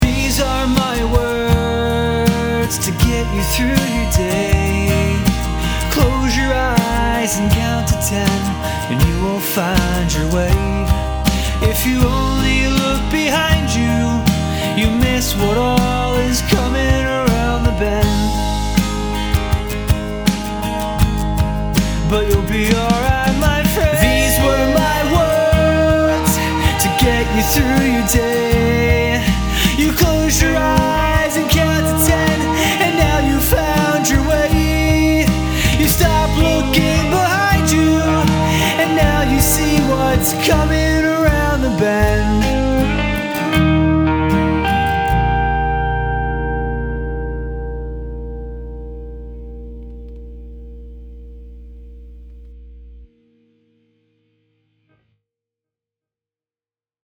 I think what I'm not liking in these recordings is HOW crisp the vocals are.... it's almost tinny. But I've messed with the EQ a bit, and this was the best I could land on.